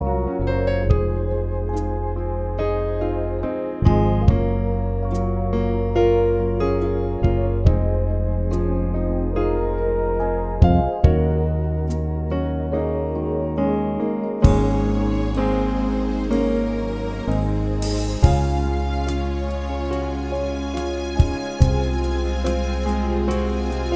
No Electric Or Acoustic Guitars Pop (1980s) 3:13 Buy £1.50